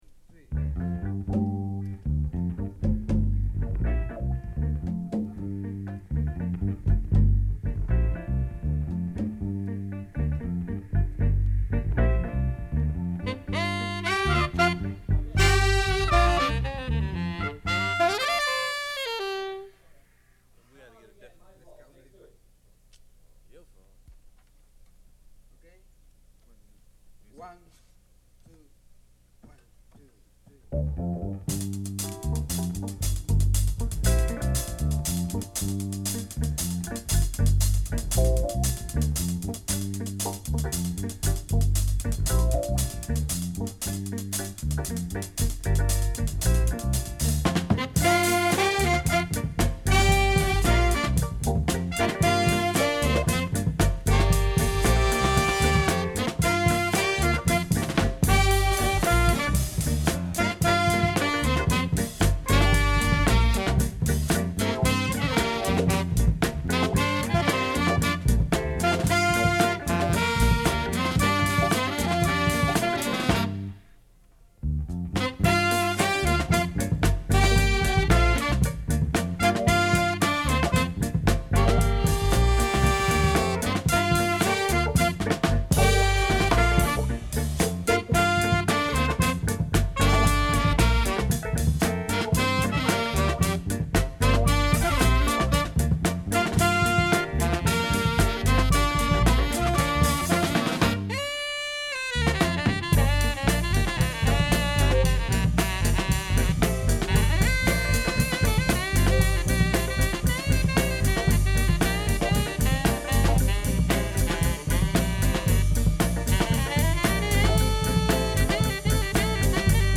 アフロ/レゲエグルーヴを感じさせる揺れるベースラインも最高です。
Afro Jazz , Ethio Jazz